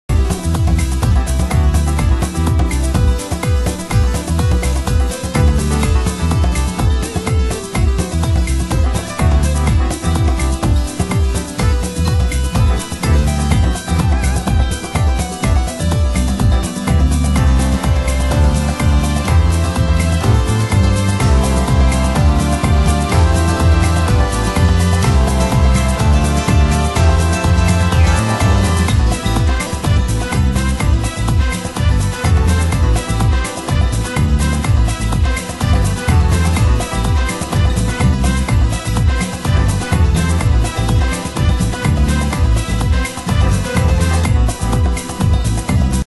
厚みのあるパーカッション、複数のKEY＆ストリングスが壮大なスケールを感じさせる、トライバルトラック！